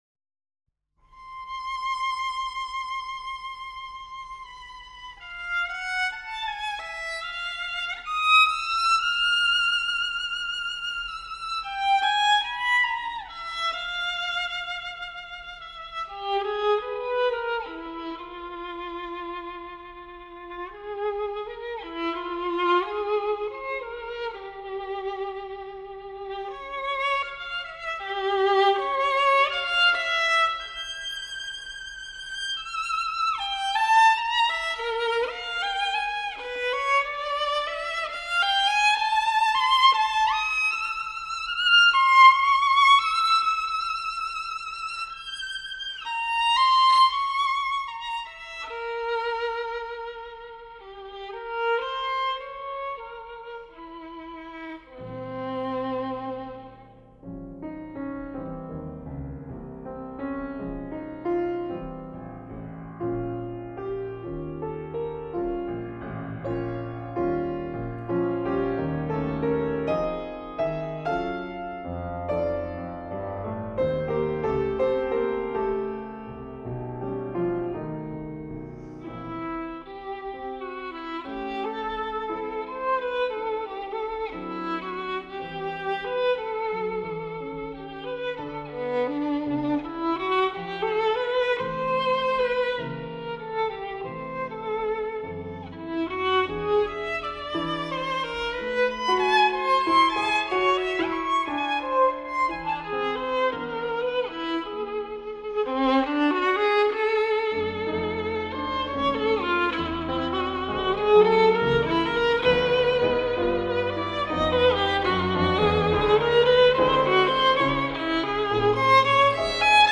Archival Recordings
Moderato tranquillo 4:22